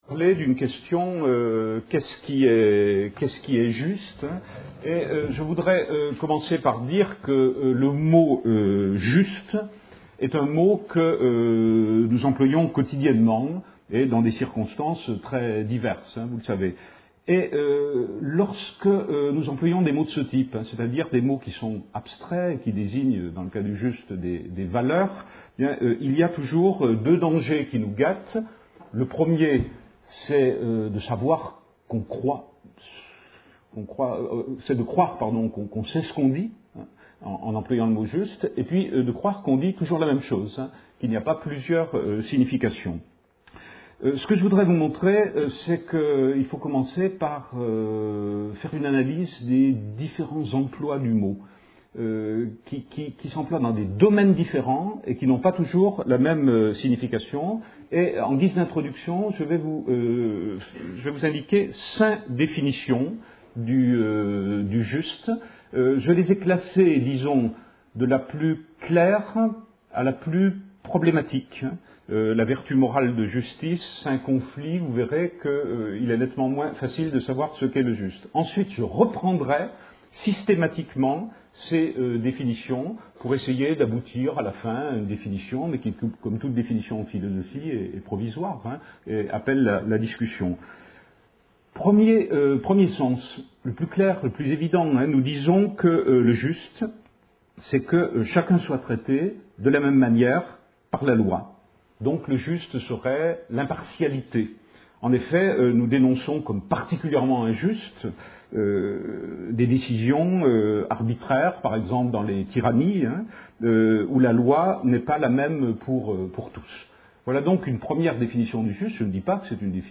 Une conférence de l'UTLS au Lycée Qu'est ce qui est juste